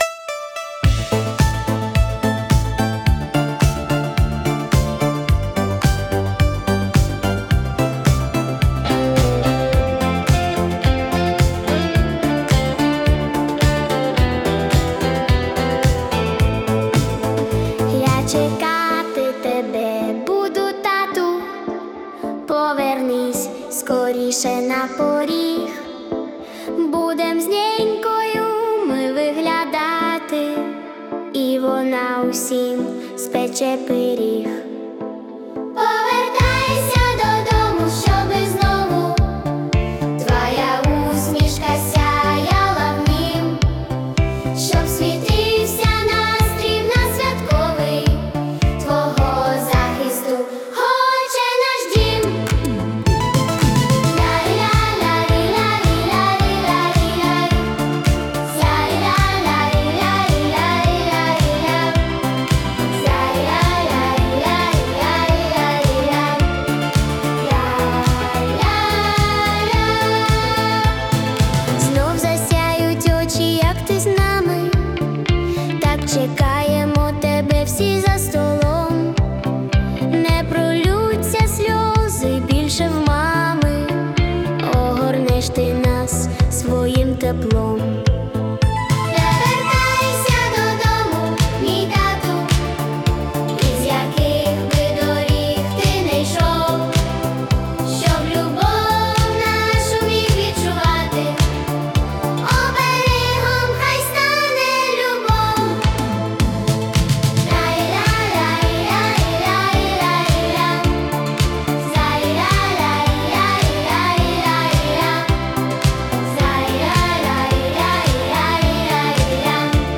🎵 Жанр: Children's War Song / Italo Disco
це зворушлива пісня у виконанні дитячого хору.